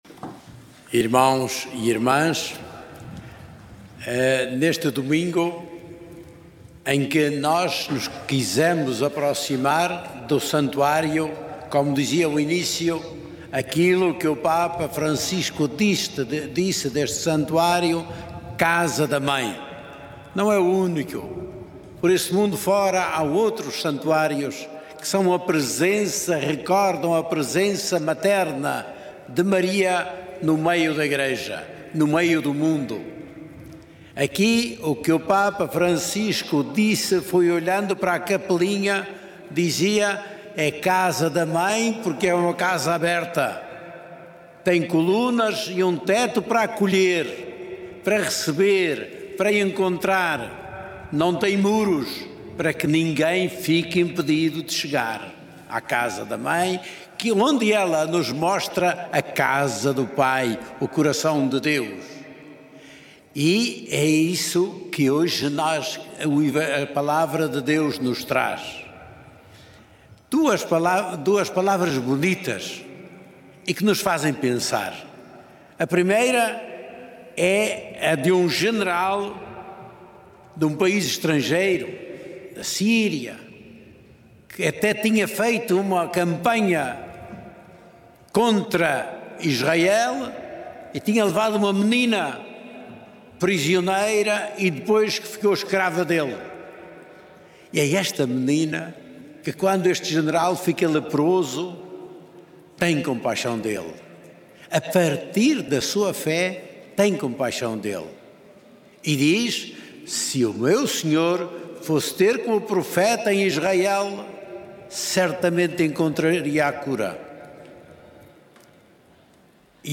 Na homilia da missa deste domingo, D. José Ornelas exortou os peregrinos reunidos no Recinto de Oração do Santuário de Fátima a escolherem o caminho do acolhimento e da integração em relação ao próximo.
homilia.mp3